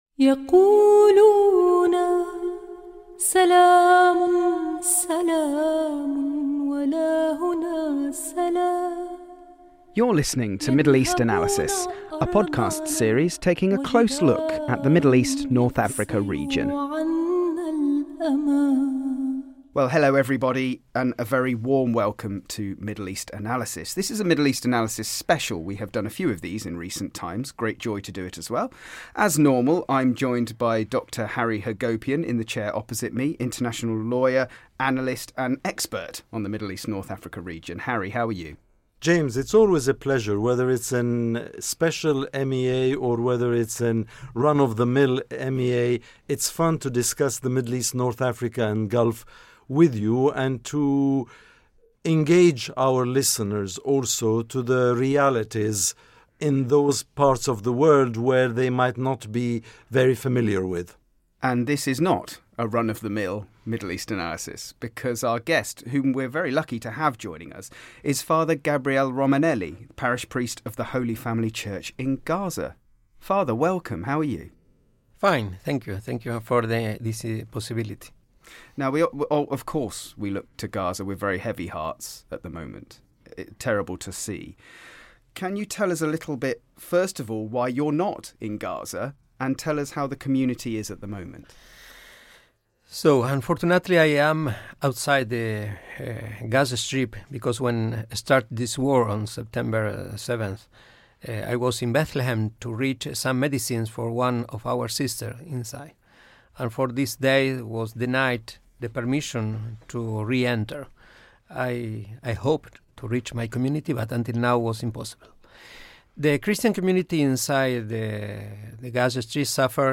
in conversation for this special podcast